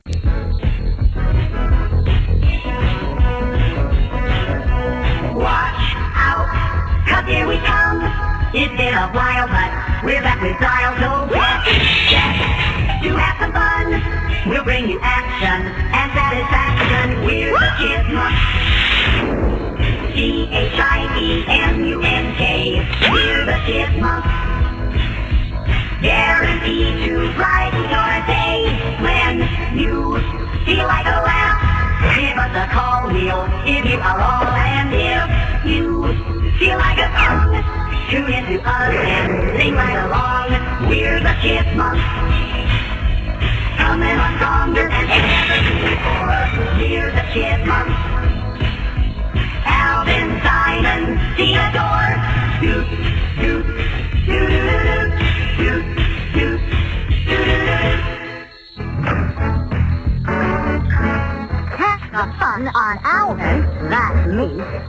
opening (cartoon series)